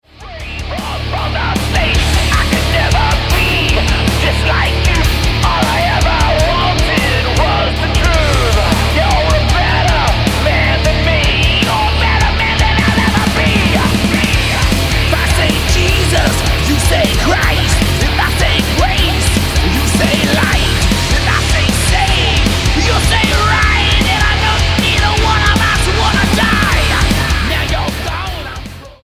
STYLE: Hard Music